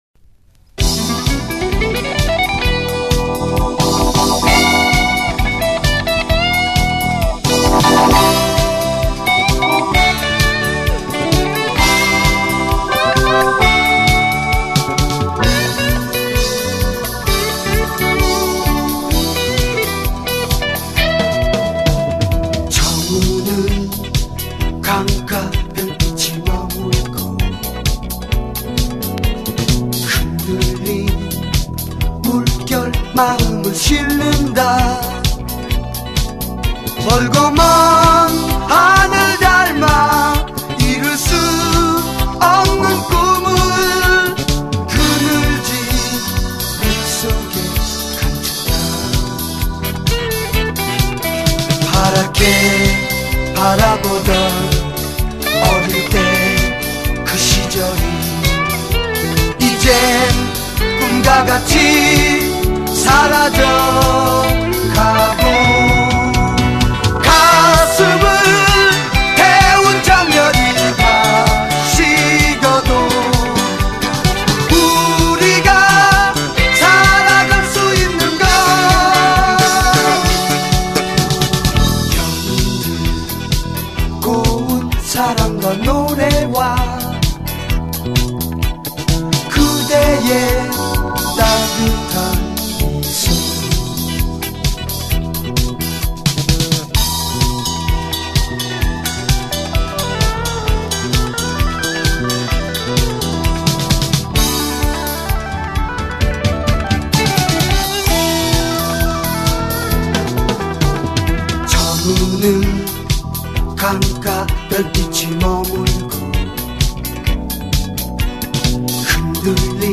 보컬
색소폰,오보에
신디사이저
드럼
베이스기타
건반